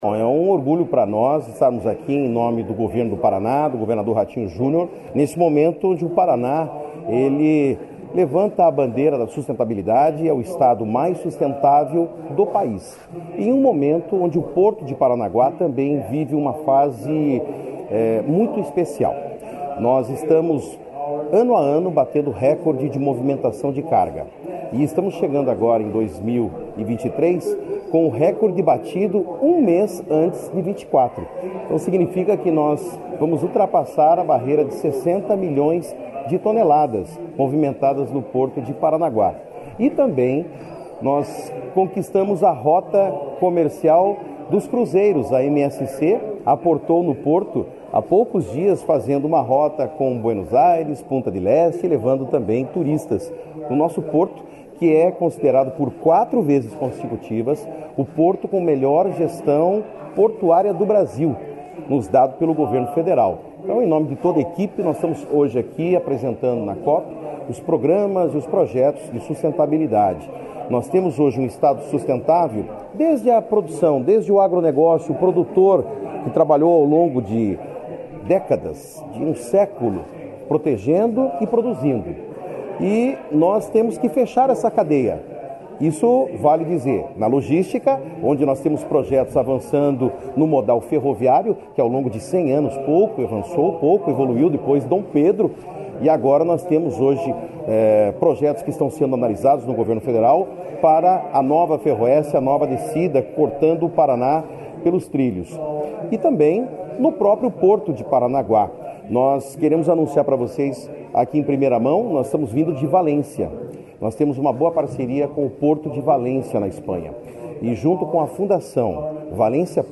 Sonora do secretário de Infraestrutura e Logística, Sandro Alex, sobre as ações socioambientais da Portos do Paraná apresentadas na COP28